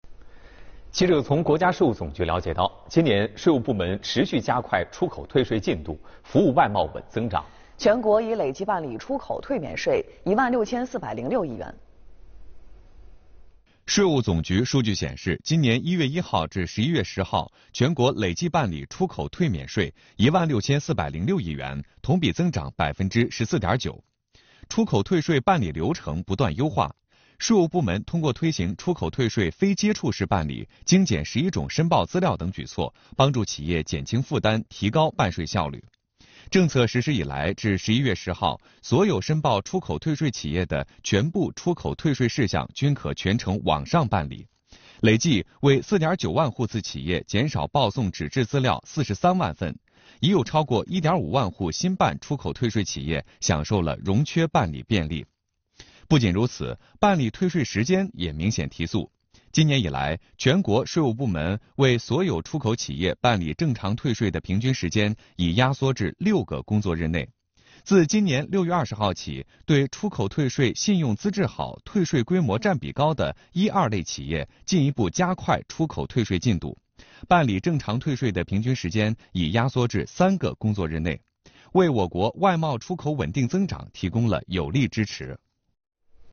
央视《朝闻天下》《新闻直播间》等栏目就此做了相关报道。
视频来源：央视《朝闻天下》